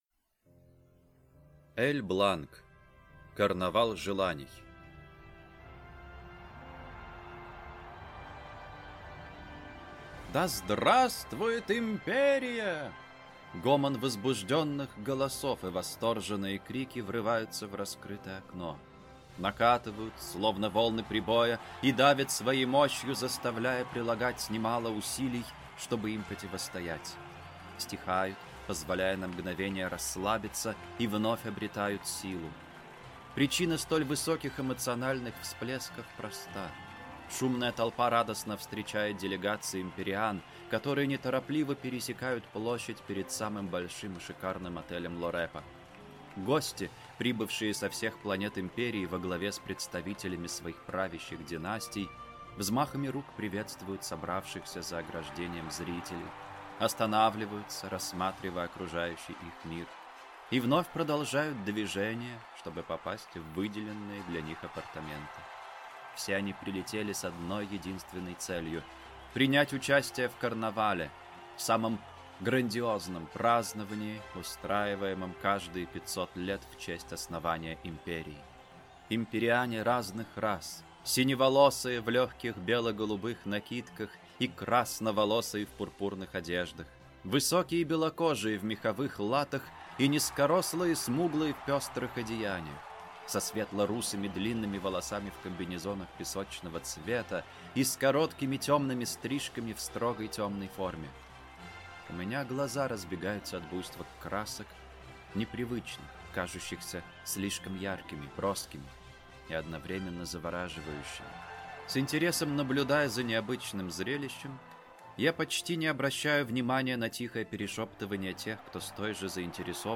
Аудиокнига Карнавал желаний | Библиотека аудиокниг